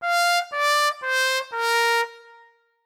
swam-trumpet_velmin_Expmax2.wav